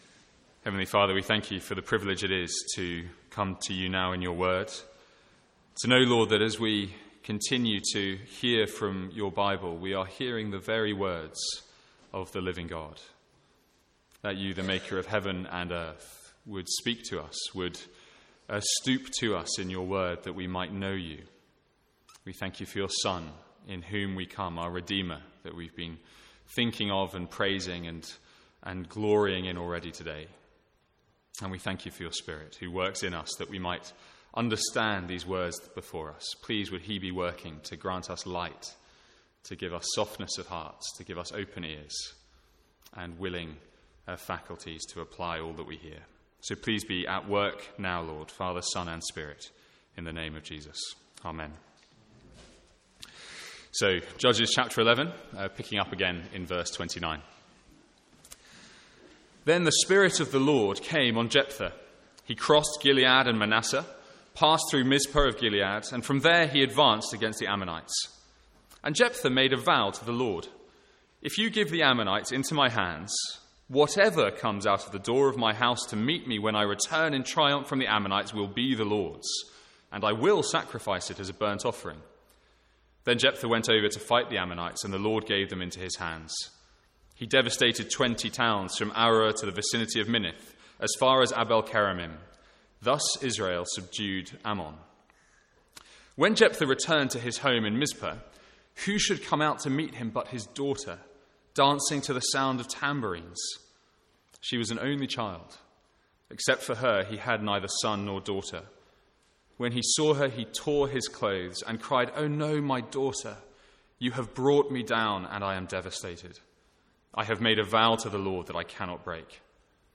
From the Sunday morning series in Judges.